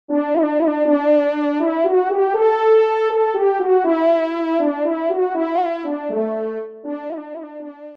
FANFARE
Pupitre de Chant